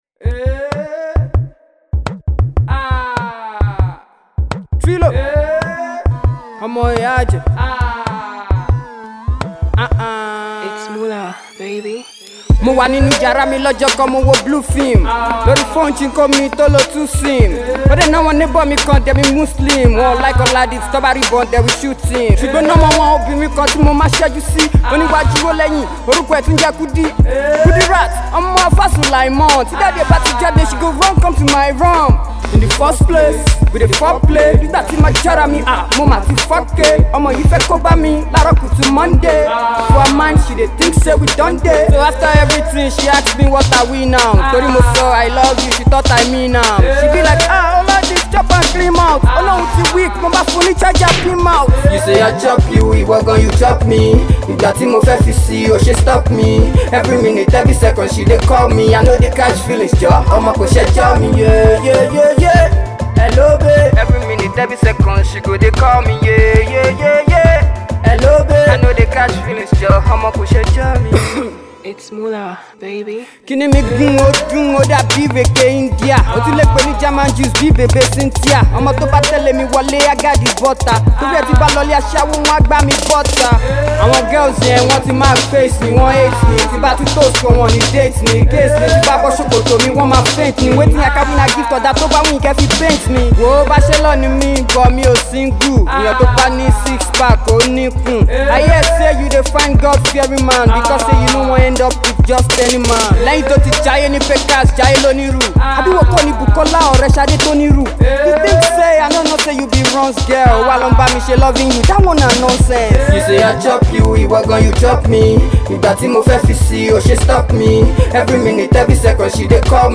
Hip-HopYoruba Music